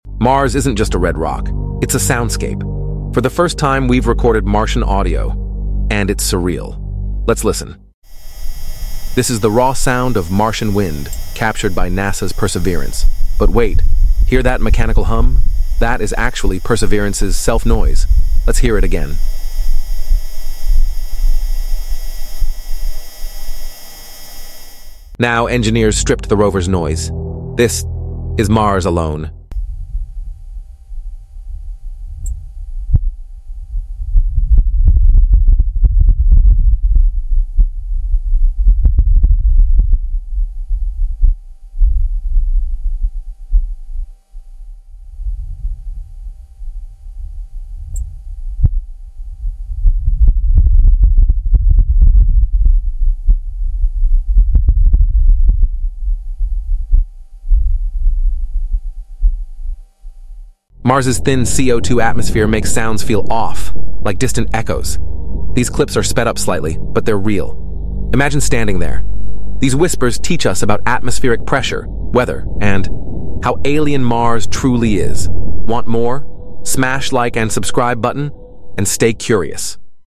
For the first time, NASA’s Perseverance rover captured raw Martian winds—and we’ve isolated the eerie, unedited audio! 🌪🔊 Discover how the Red Planet’s thin atmosphere creates haunting whispers you’ve never heard before.